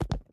land.ogg